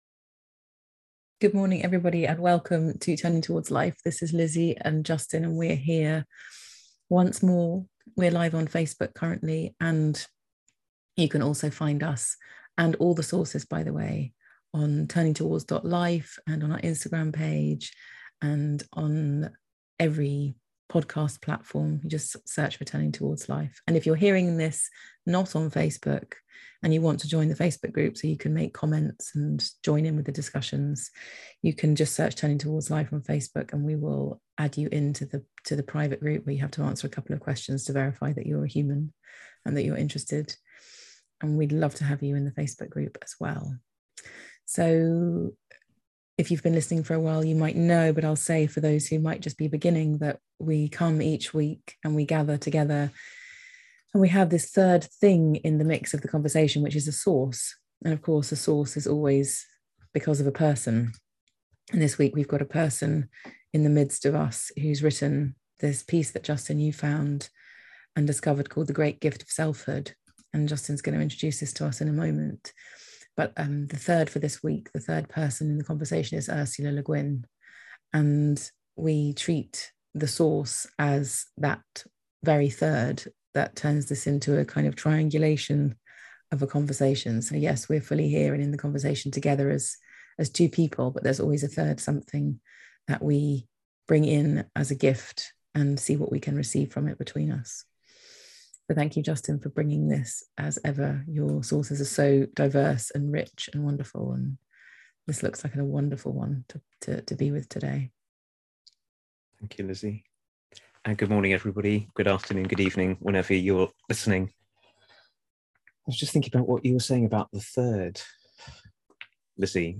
And so this week's Turning Towards Life is a conversation about what might become possible if we understand ourselves and everyone around us as flow, arising from the bigger flow of life or existence itself.